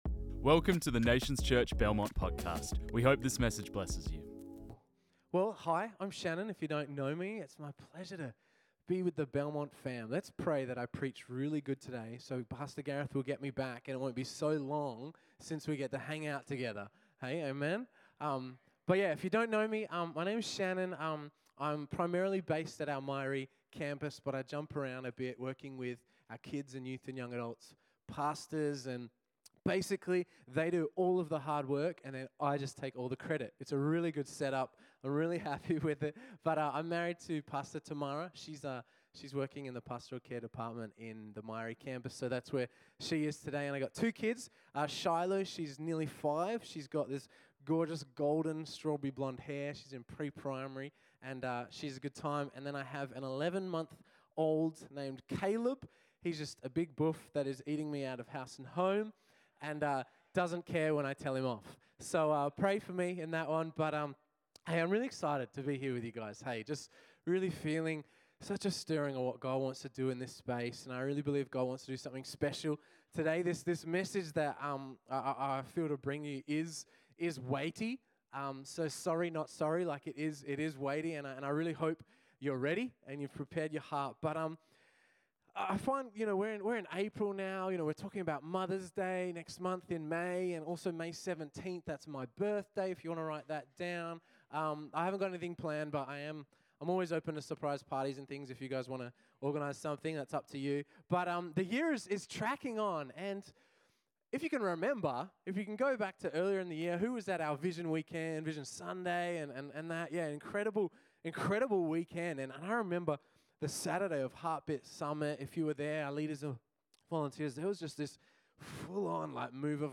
This message was preached on 30 April 2023.